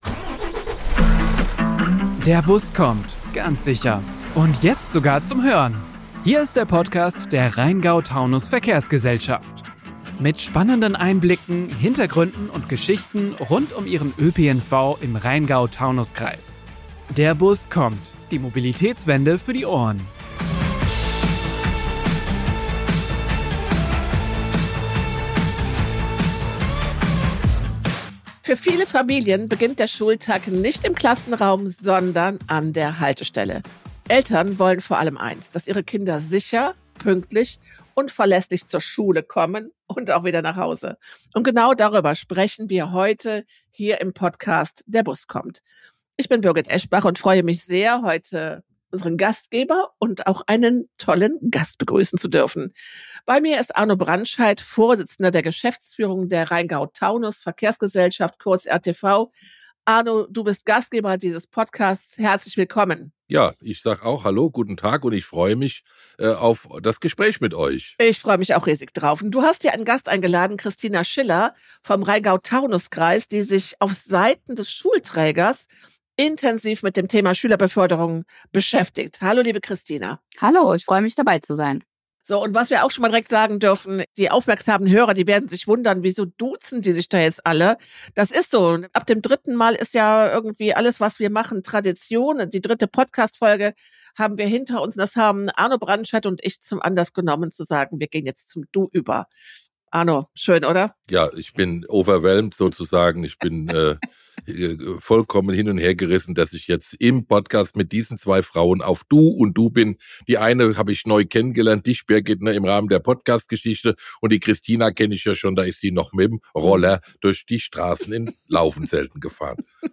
Im Mittelpunkt des Gesprächs stehen die komplexen Herausforderungen bei der Schülerbeförderung und bei der sicheren und pünktlichen Ankunft der Kinder und Jugendlichen in der Schule.